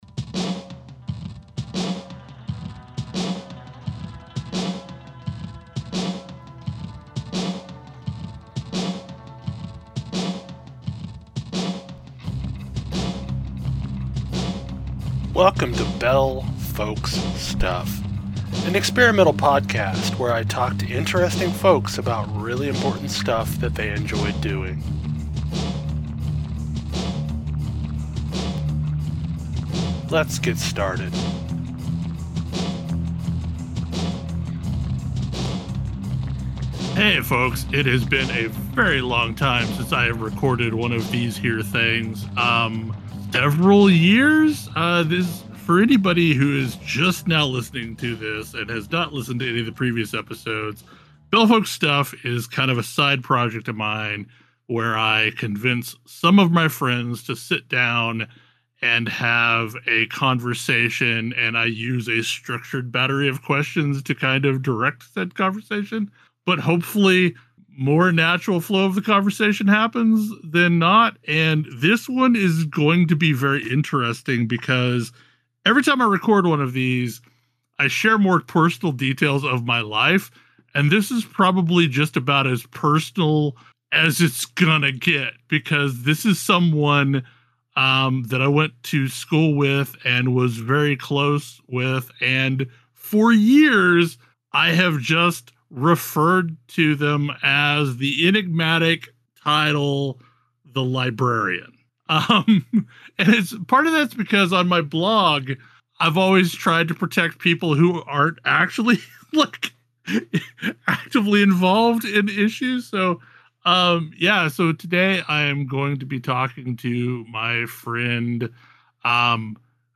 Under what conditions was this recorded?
This took up roughly two hours of real-world time that got edited down into an hour and twenty minutes once I compressed the silence.